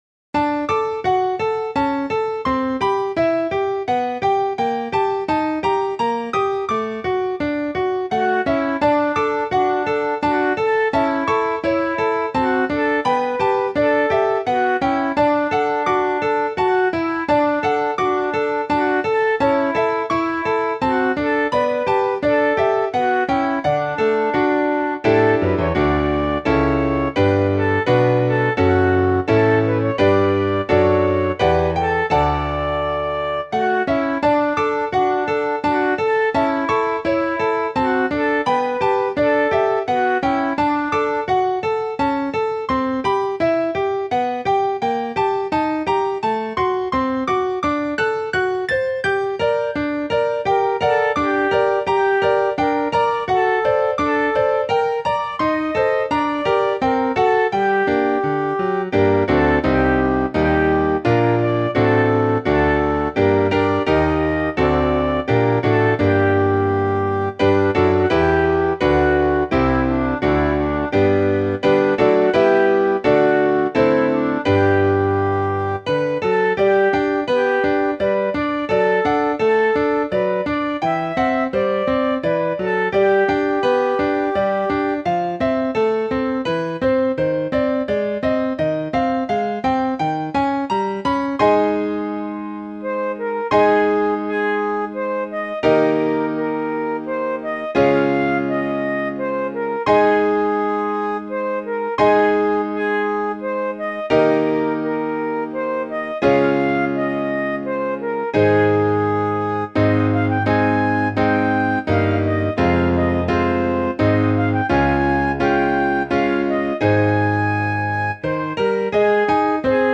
Voicing/Instrumentation: Vocal Solo